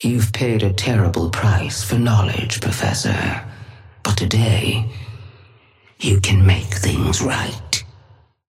Sapphire Flame voice line - You've paid a terrible price for knowledge, Professor. But today, you can make things right.
Patron_female_ally_dynamo_start_01.mp3